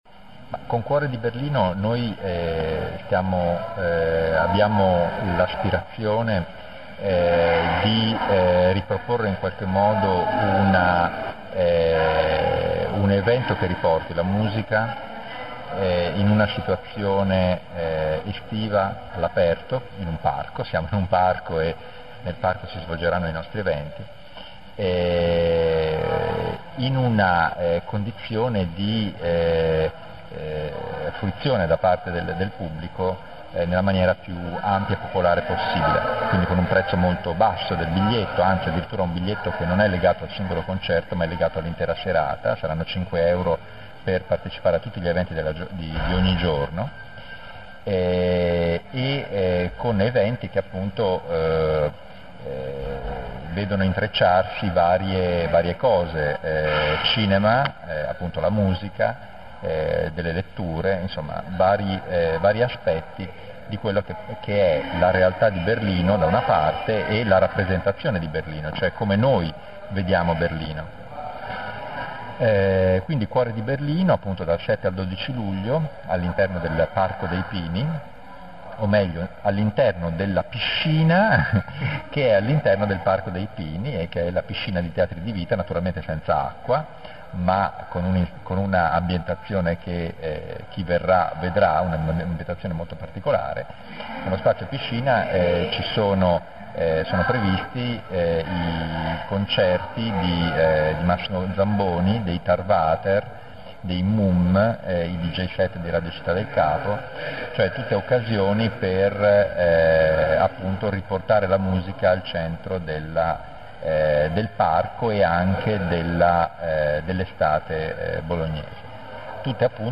cuore_berlino_intervista.mp3